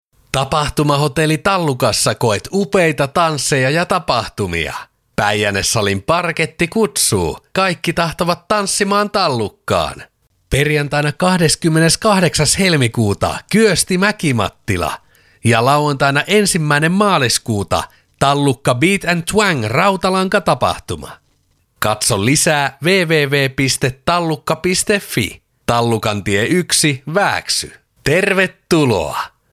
Kuuntele tästä viimeisin radiomainontamme Järviradiossa: